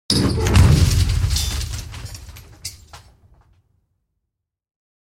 Dry_Rusty_Metal_Impact_With_Collapse_08.ogg